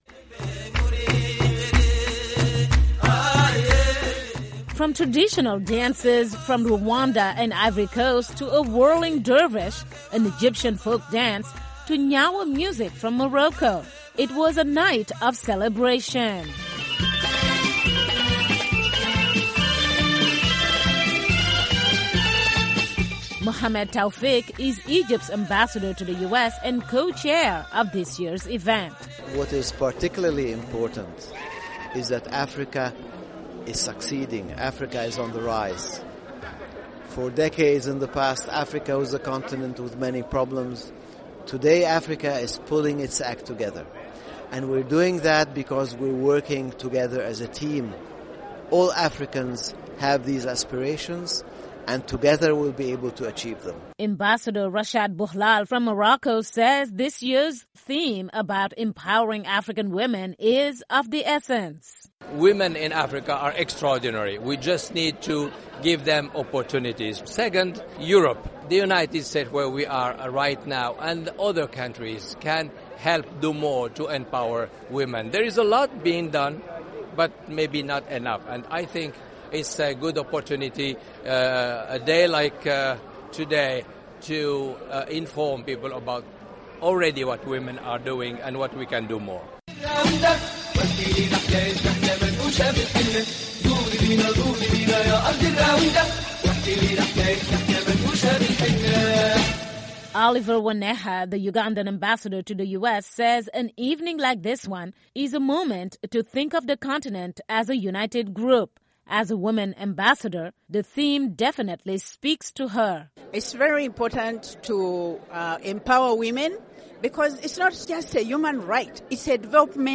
A gala -- based on this year’s theme: Women Empowerment & Development! -- topped off celebrations.
From traditional dances from Rwanda and Ivory Coast to a whirling dervish, an Egyptian folk dance to Gnawa music from Morocco, it was a night of celebration.